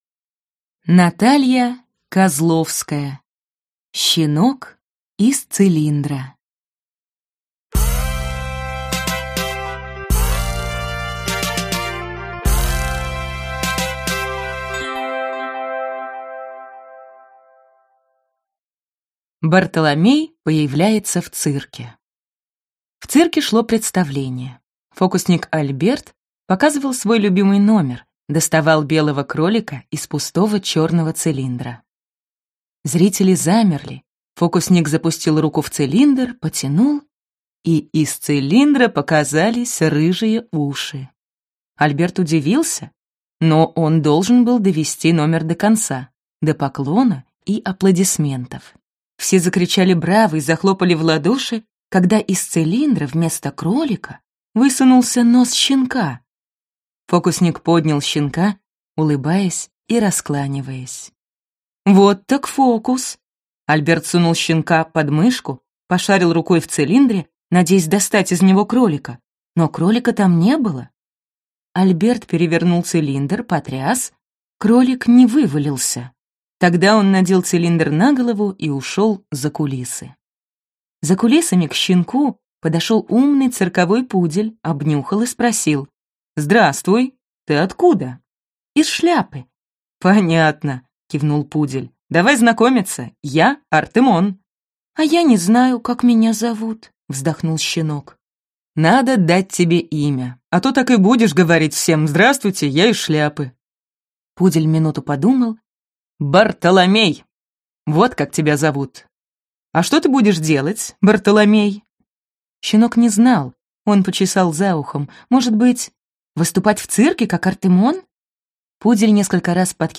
Аудиокнига Щенок из цилиндра | Библиотека аудиокниг